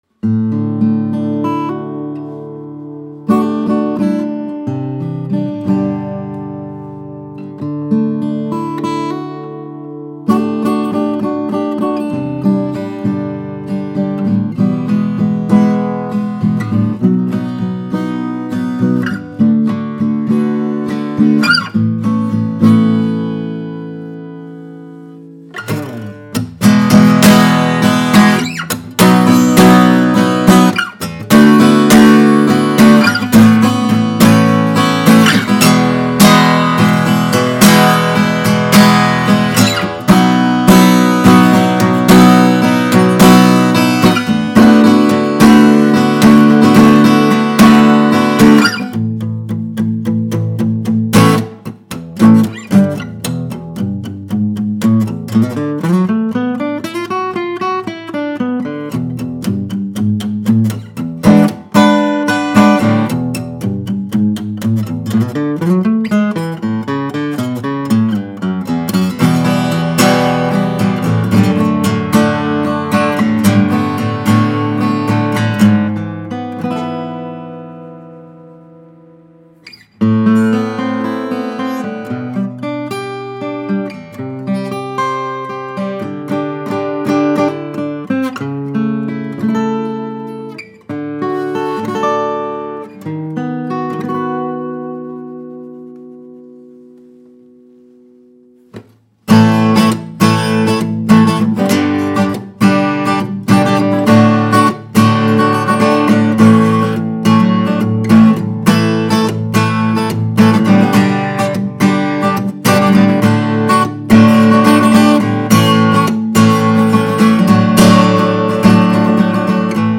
Santa Cruz 000 Custom with Indian Rosewood and mastergrade German Spruce. Time-tested 12-fret blues & fingerpicking guitar.
Sound-impression-Santa-Cruz-000-Custom.mp3